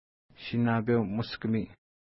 Pronunciation: ʃi:na:pew-muskəmi: